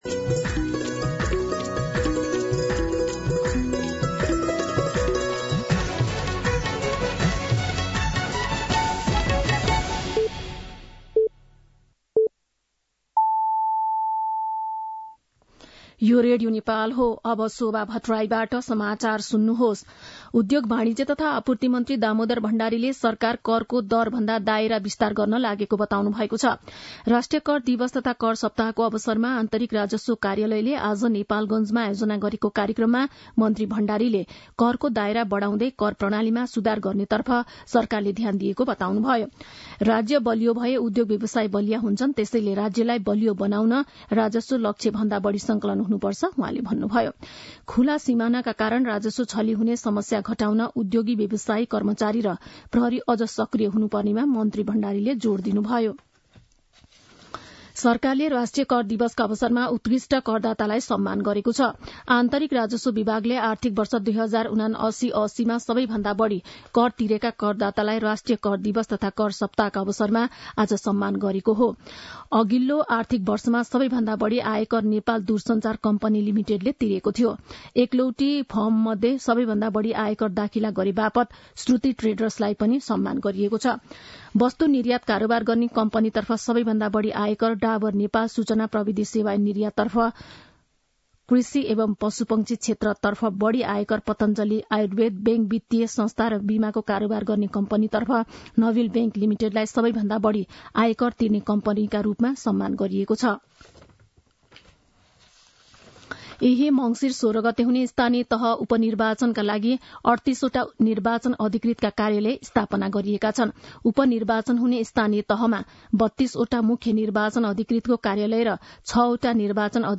दिउँसो १ बजेको नेपाली समाचार : २ मंसिर , २०८१
1-pm-Nepali-News-2.mp3